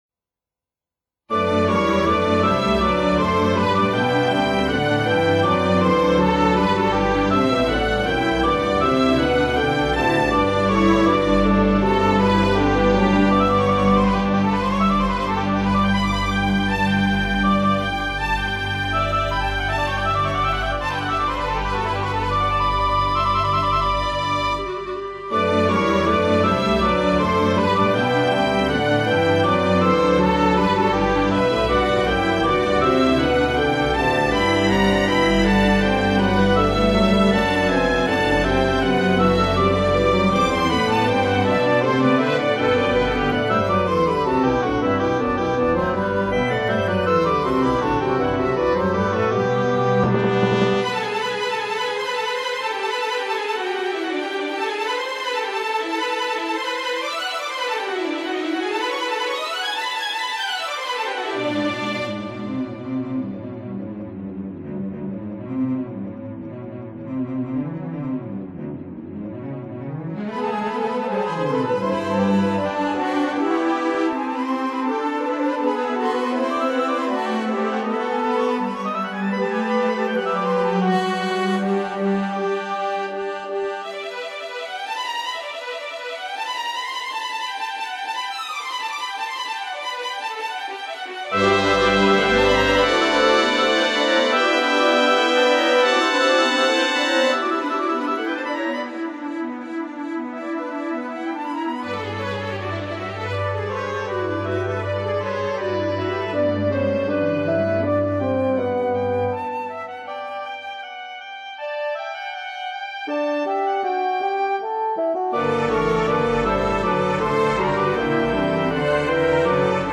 1st Symphony.